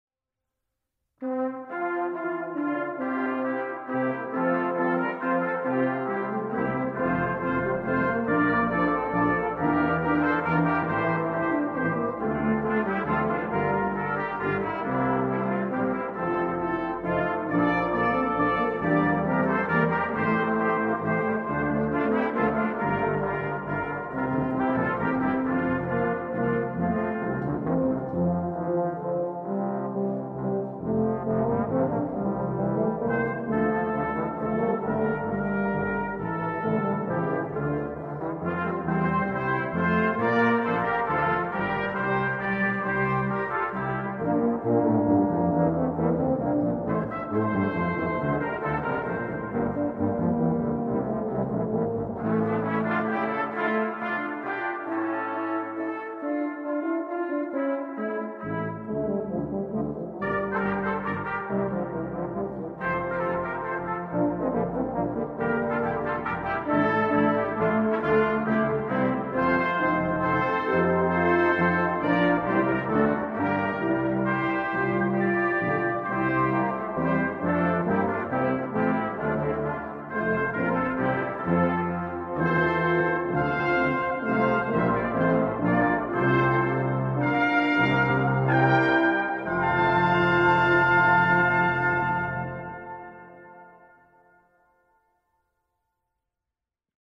Die Bläserarbeit an St. Michael hat eine lange Tradition.
Unser Klang